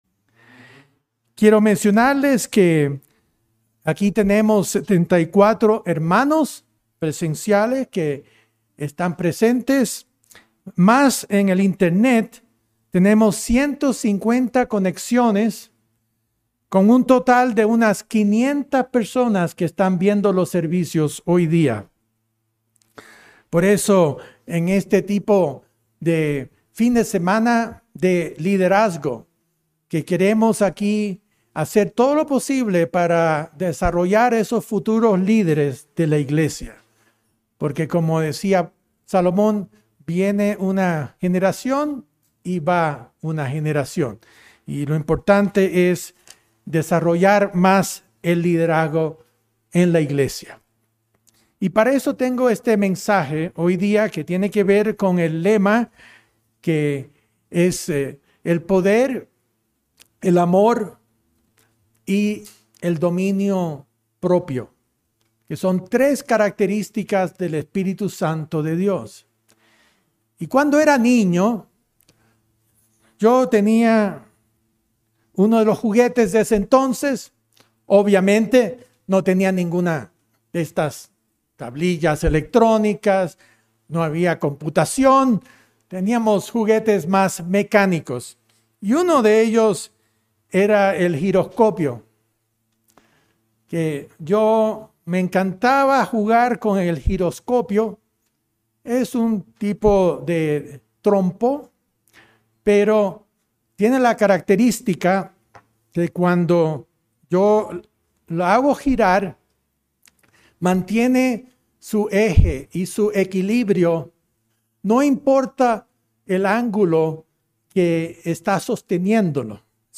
Mensaje entregado el 27 de agosto de 2022.